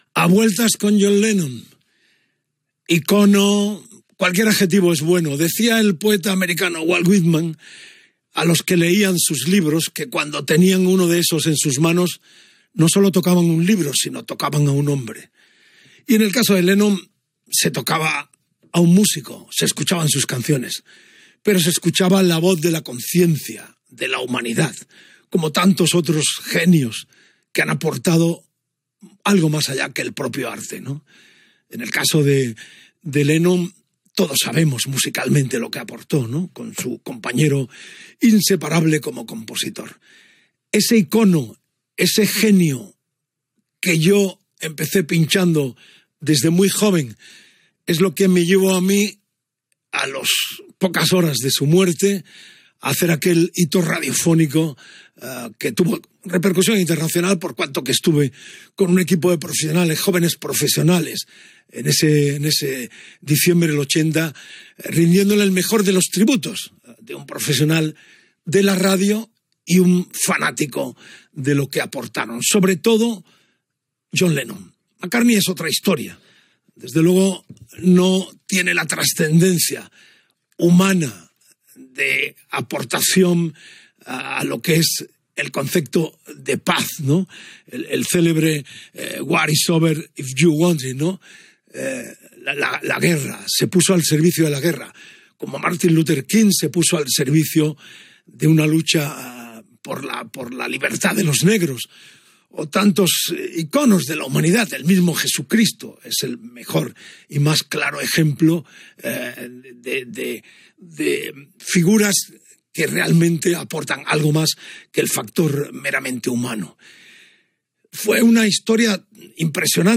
Valoració de la figura musical de John Lennon Gènere radiofònic Musical